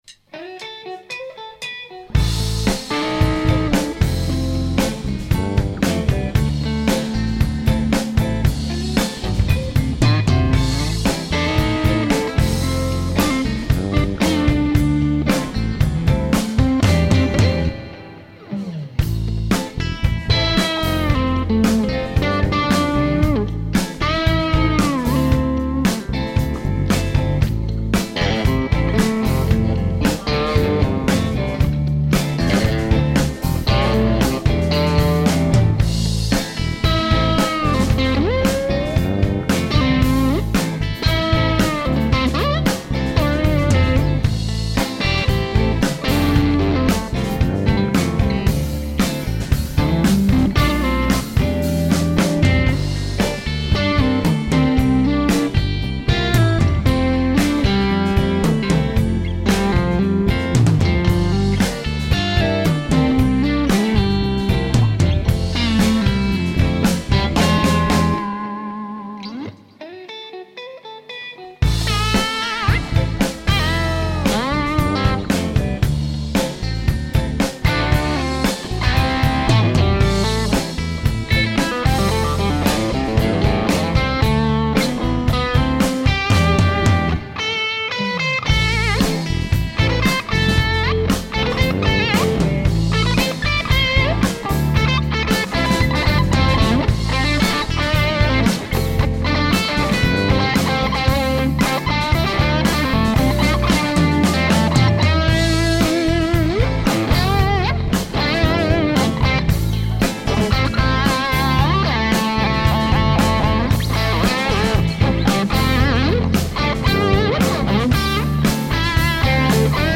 so ein echt eingespieltes Schlagzeug ist schon schick.
Vorhin habe ich mal kurz Zeit gefunden, ein wenig dazu zu spielen, wie gewohnt bei mir in einem Rutsch durch und mit kleinen Ecken und Kanten für´s Livefeeling :-) Zu hören ist die Tom Launhardt - Strat über Velocette und 1x12 Box, davor Cmatmods Brownie, MI Neo Fuzz und ein Boss Delay, gespielt in Zimmerlautstärke.